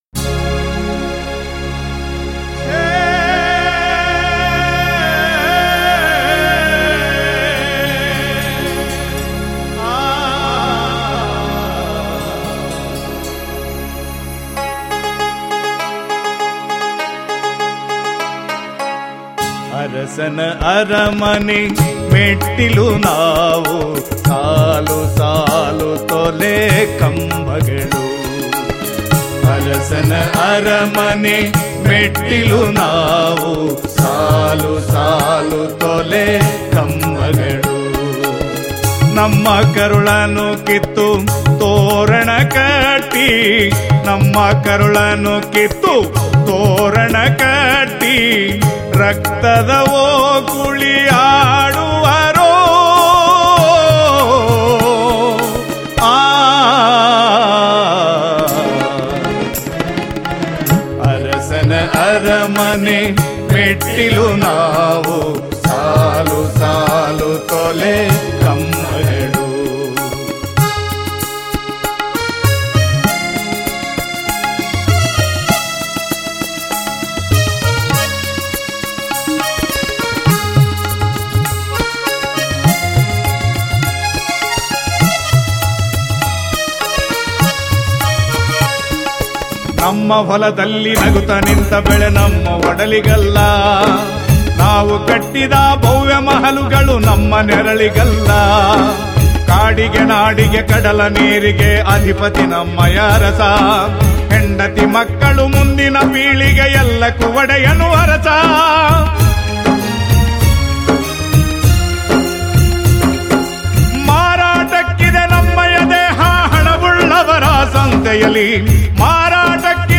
ಸೂರ್ಯ ಶಿಕಾರಿ ನಾಟಕದಲ್ಲಿ ಅಳವಡಿಸಿಕೊಂಡಿದ್ದ ರಂಗಗೀತೆ👇